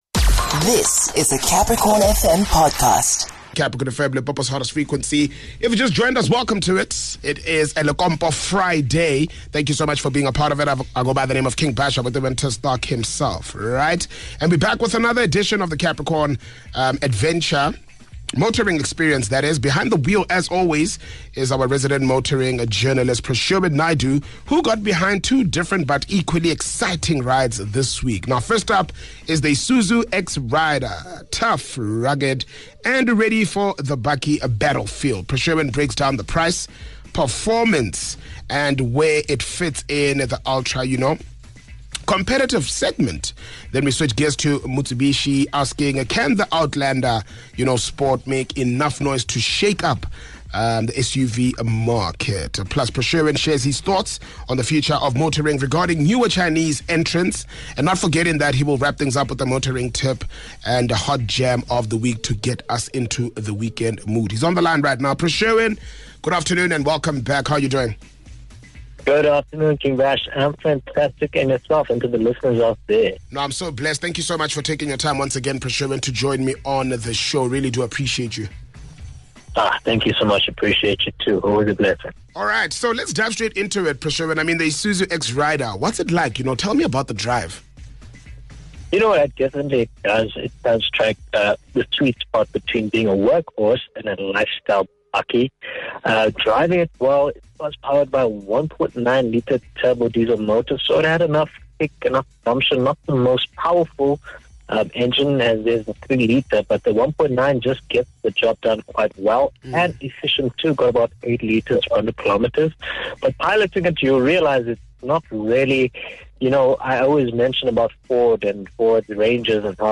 joined on the line